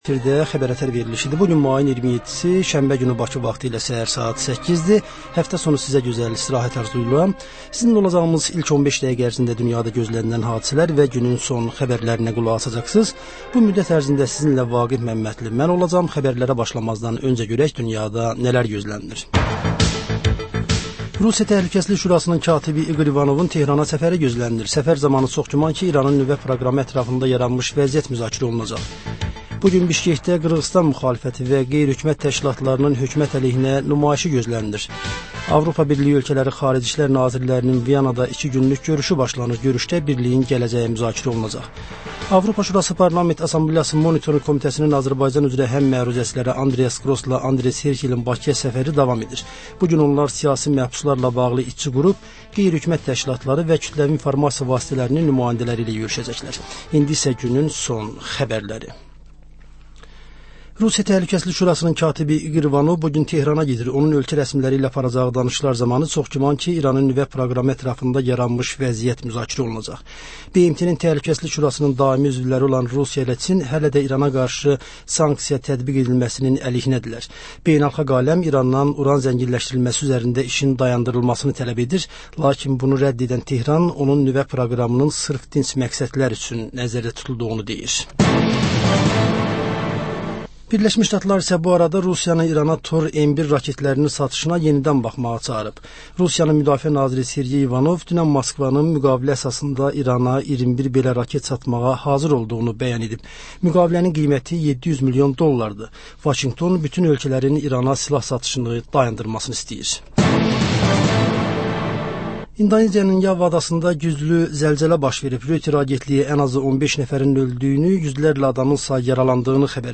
S əhər-səhər, Xəbər-ətər: xəbərlər, reportajlar, müsahibələrVə: Canlı efirdə dəyirmi masa söhbətinin təkrarı.